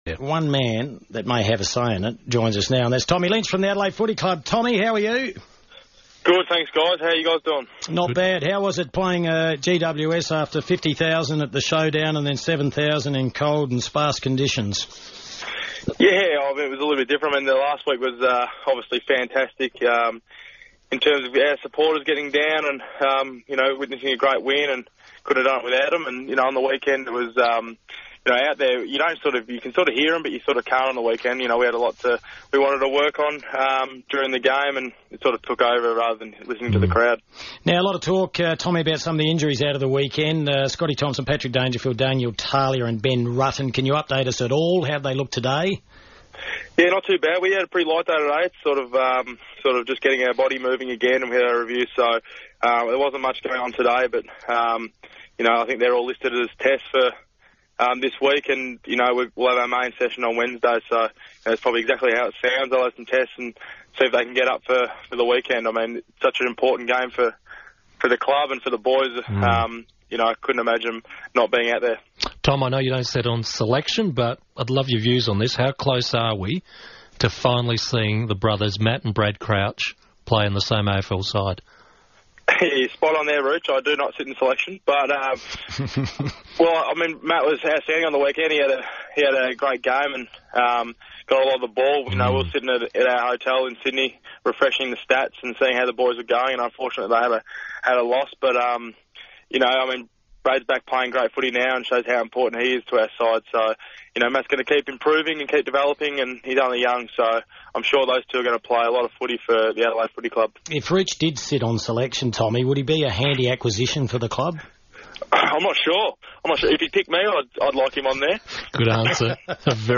Tom Lynch joined the FIVEaa Sports Show following Adelaide's impressive victory over the Giants